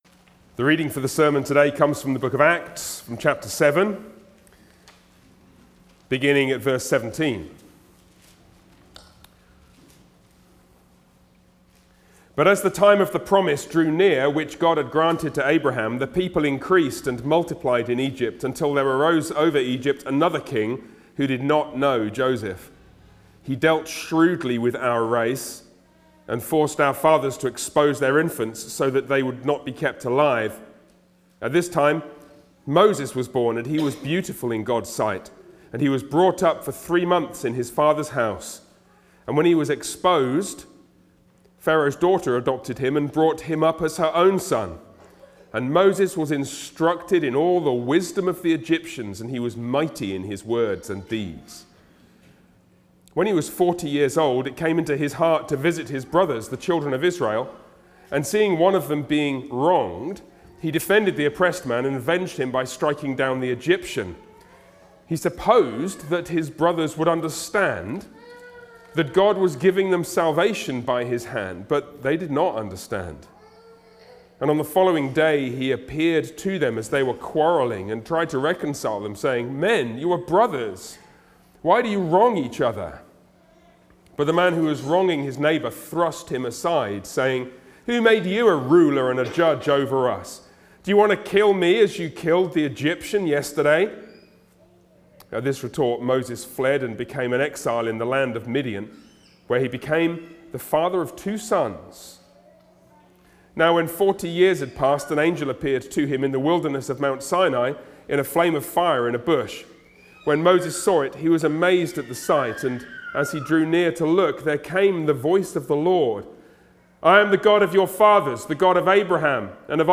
Series: Sermons on Acts Passage: Acts 7:17-45 Service Type: Sunday worship